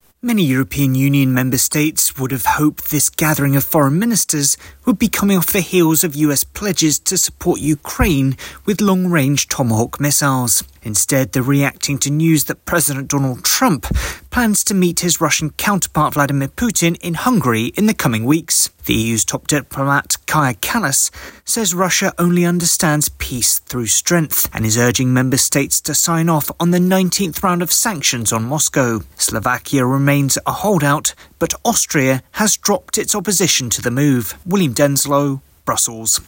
reports from Brussels